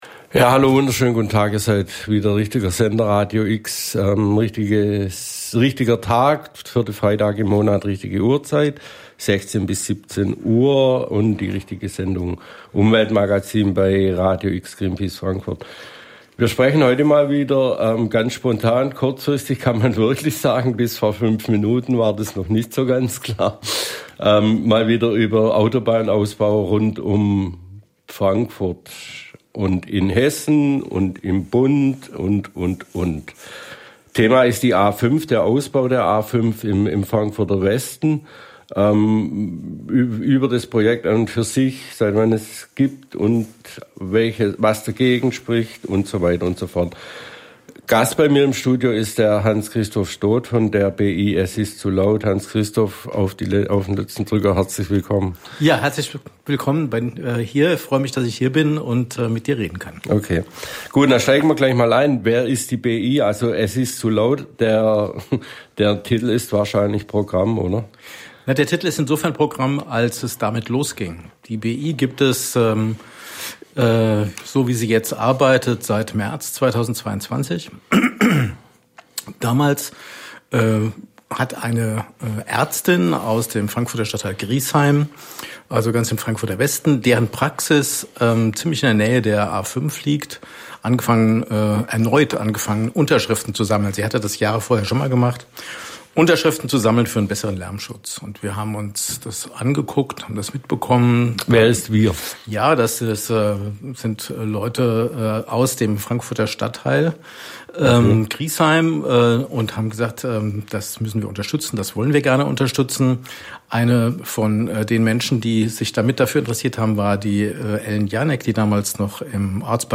Interview im Radio X Umweltmagazin
Die BI ist erneut zu Gast bei Radio X, diesmal beim Umweltmagazin